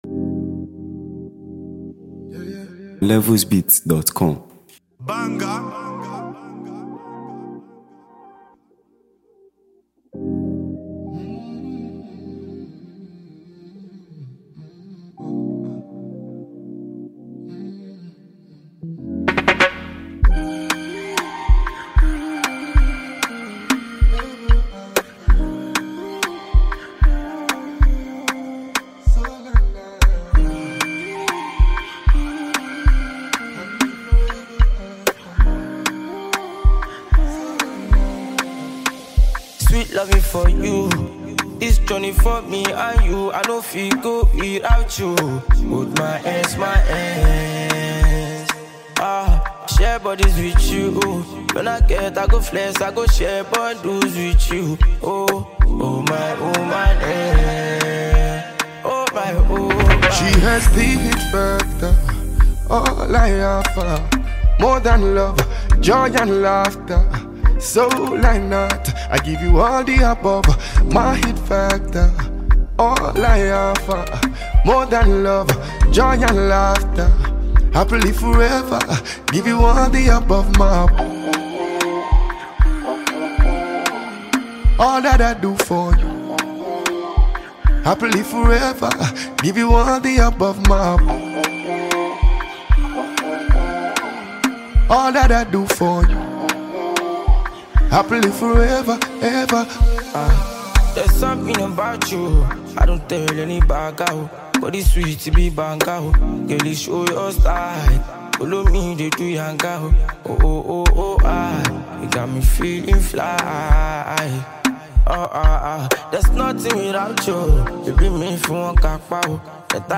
signature Afrobeat vibes